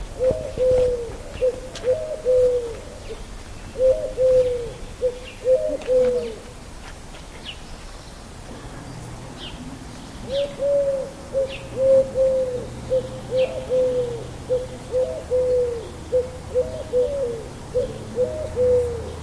Birdsong » pigeon
描述：The call of a woodpigeon recorded in EnglandTascam DR03 Mk2
标签： coo dove caw birdsong call pigeon woodpigeon
声道立体声